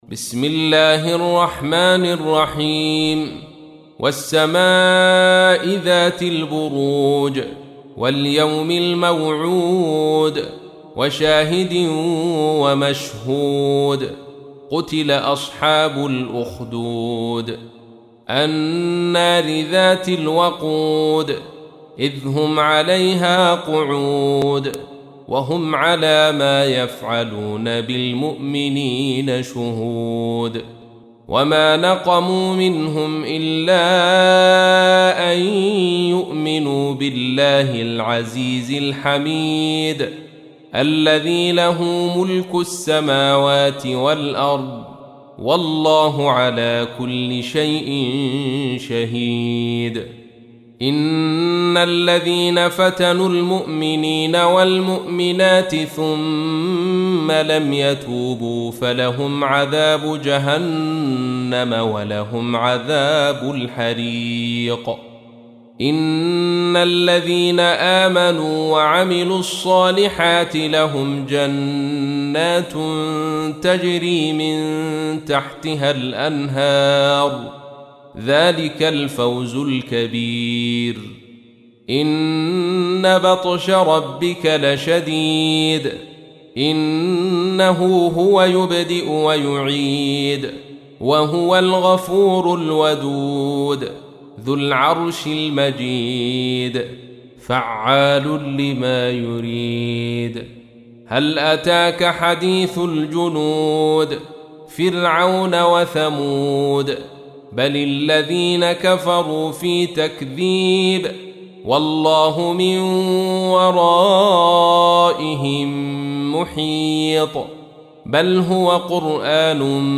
تحميل : 85. سورة البروج / القارئ عبد الرشيد صوفي / القرآن الكريم / موقع يا حسين